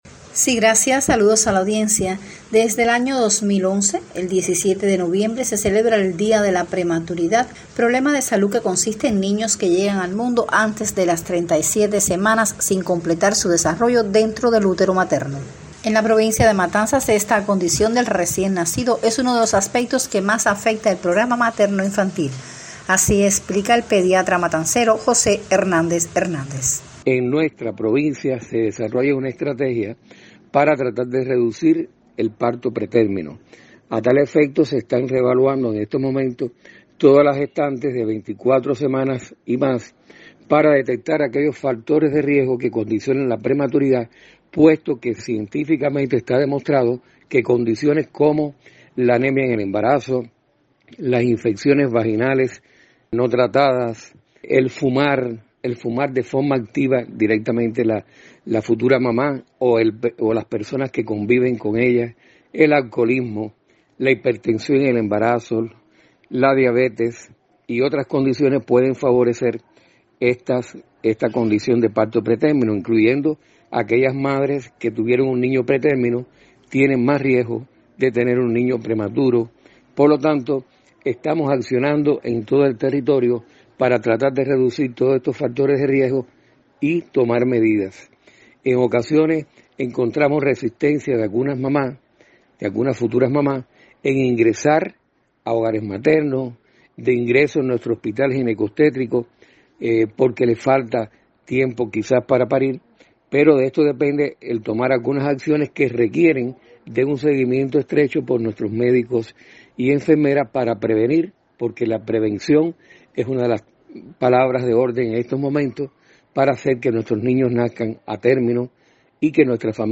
así lo explica el pediatra matancero